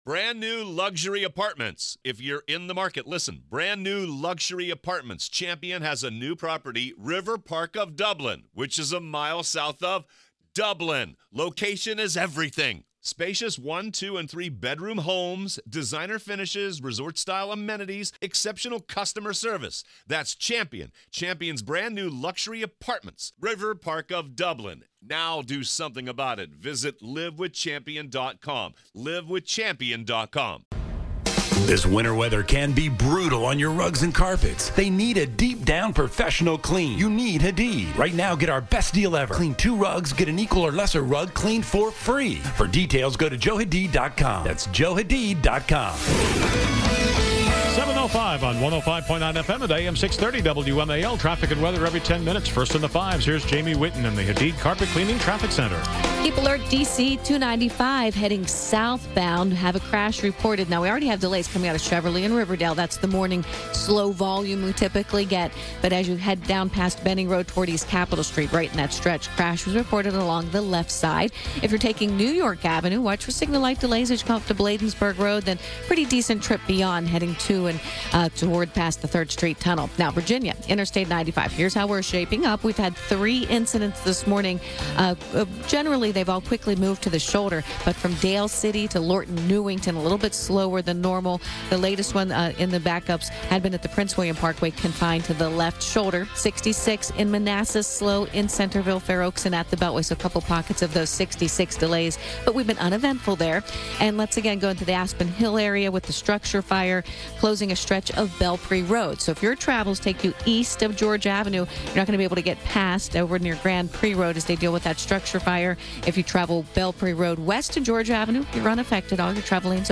broadcast live from CPAC